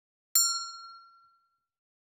new_message.ogg